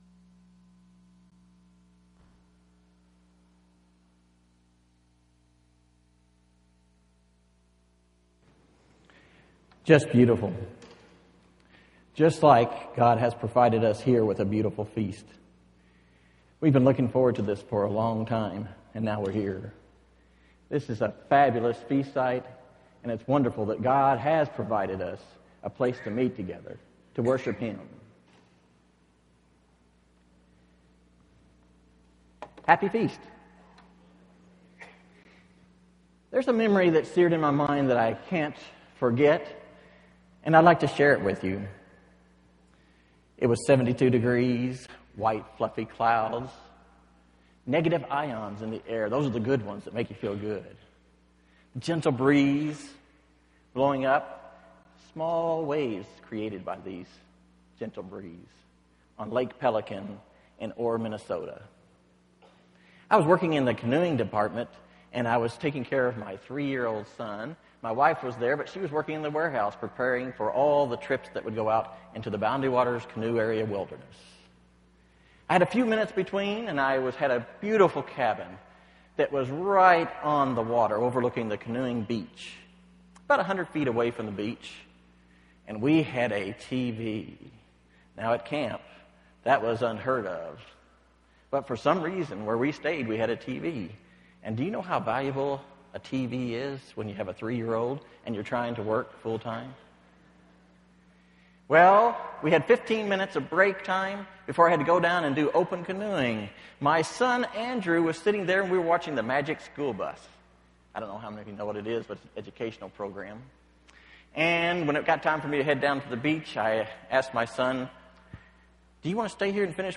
This sermon was given at the Sevierville, Tennessee 2015 Feast site.